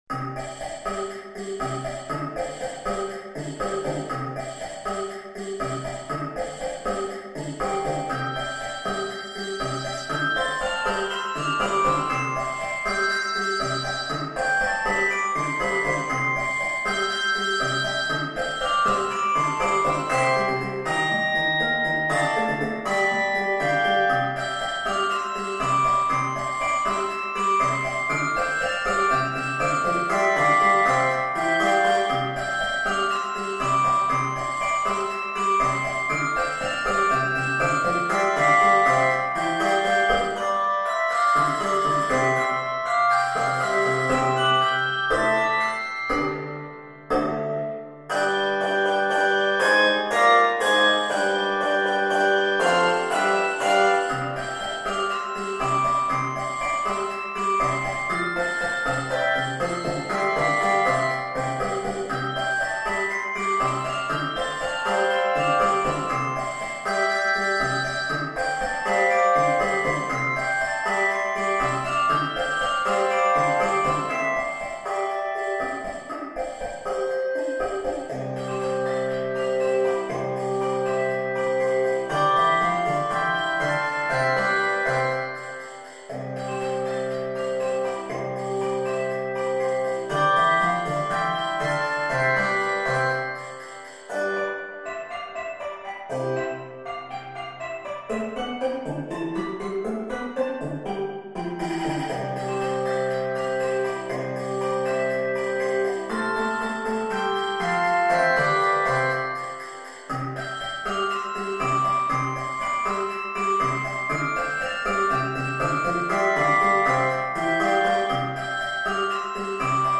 N/A Octaves: 5 Level